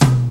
44_29_tom.wav